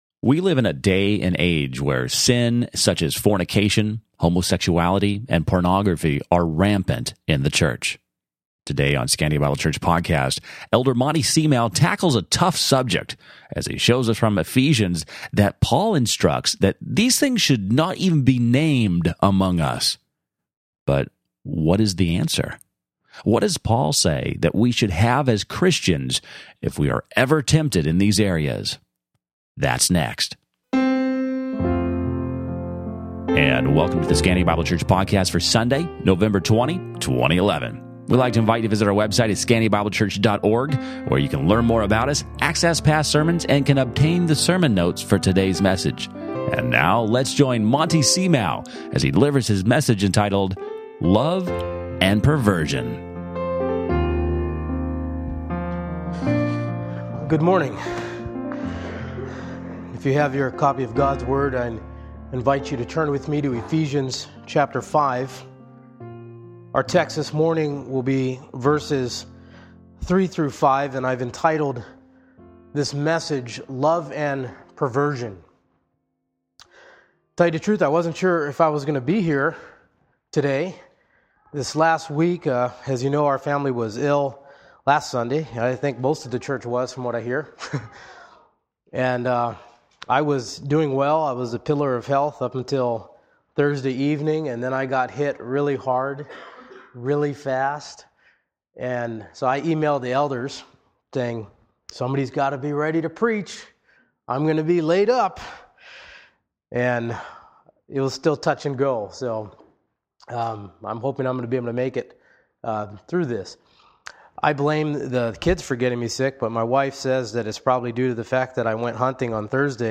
Sermon Notes
(**Note: Unfortunately this recording had a few audio “drop-outs.” They were technical in nature and not something we intentionally edited or withheld).